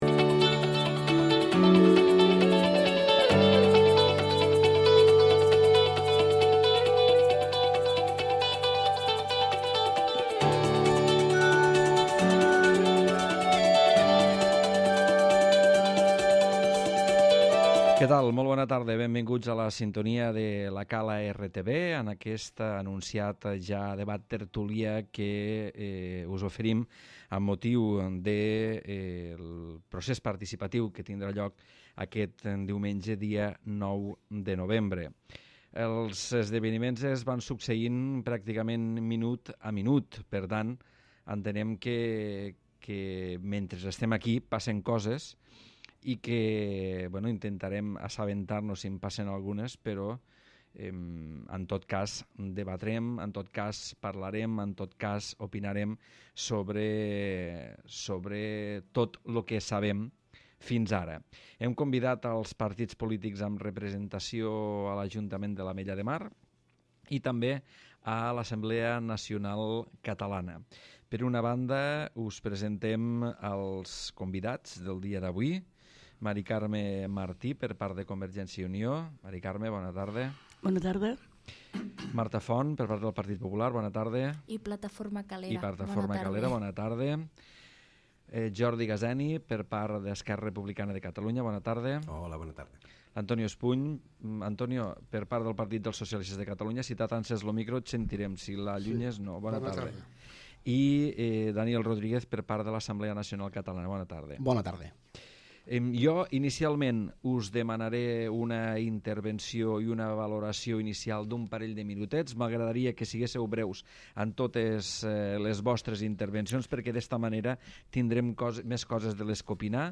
Debat Procés Participatiu 9N